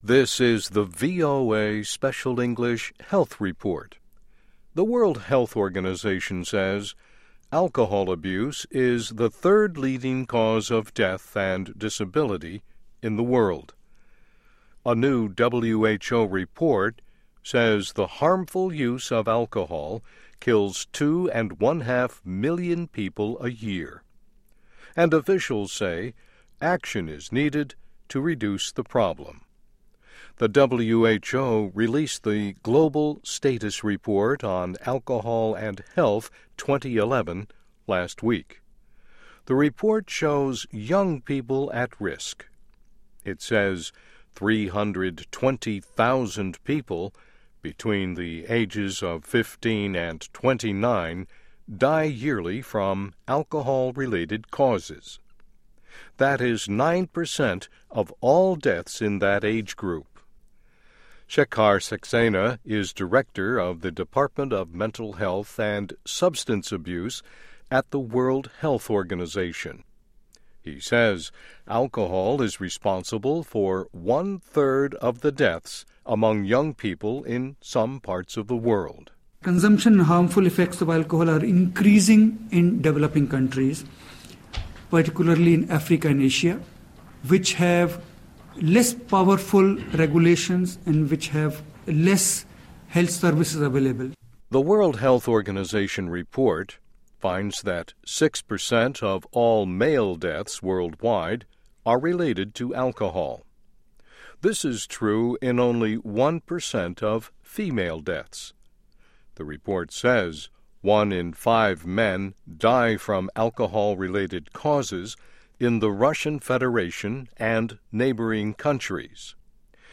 Health Report